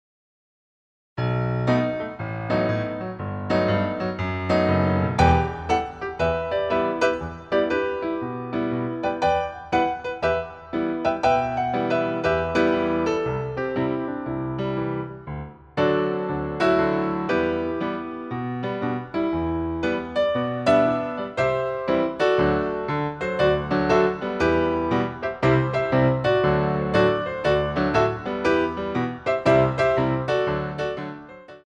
BATTEMENT TENDU II